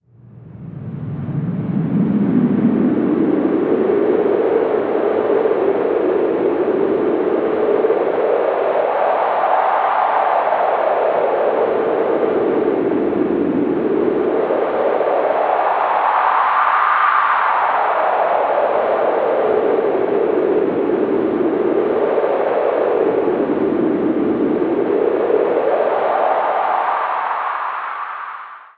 Звуки ветра
Аудиоэффект порыв ветра